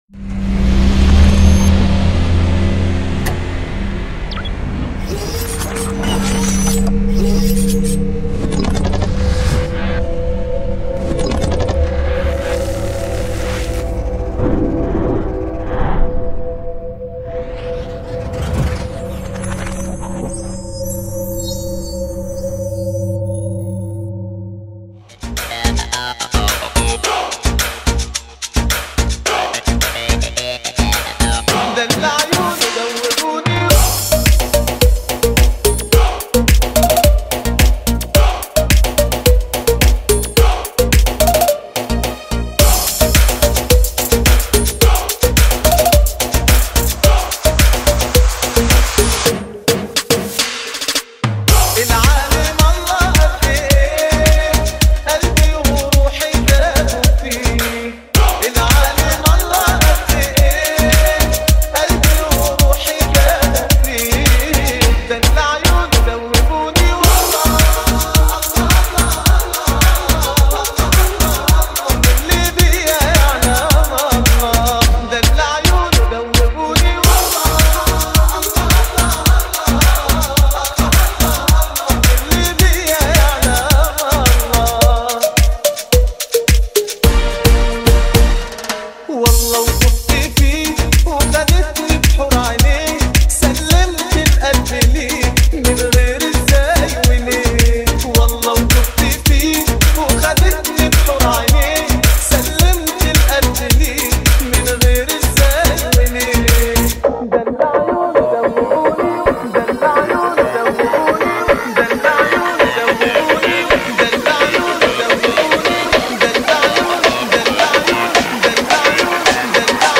اهنگ شاد عربی